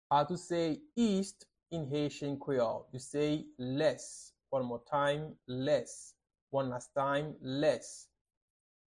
How to say "East" in Haitian Creole - "Lès" pronunciation by a native Haitian tutor
“Lès” Pronunciation in Haitian Creole by a native Haitian can be heard in the audio here or in the video below:
How-to-say-East-in-Haitian-Creole-Les-pronunciation-by-a-native-Haitian-tutor.mp3